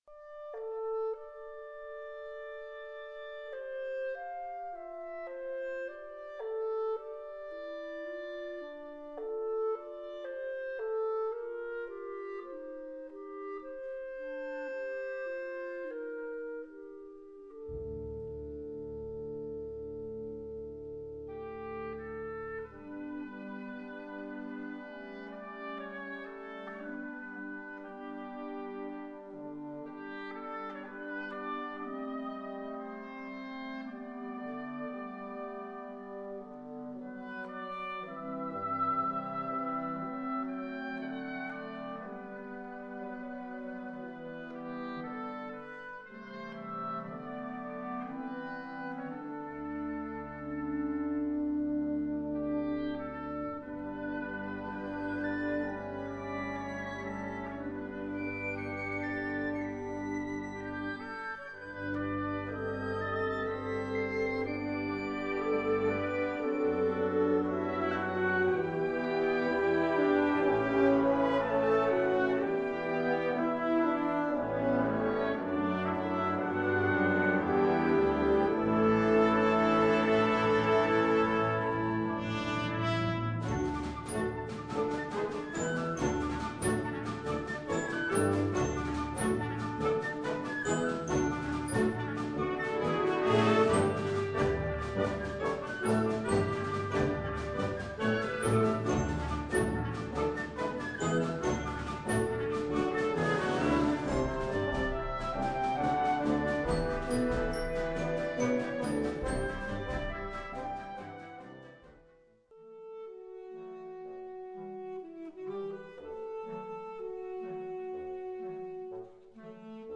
Catégorie Harmonie/Fanfare/Brass-band
Sous-catégorie Musique de concert
Instrumentation Ha (orchestre d'harmonie)
Informations complémentaires/contenu I. Largo: Elsi
V. Allegro vivace: Krieg
VI. Largo delicato e quasi etereo: Abschied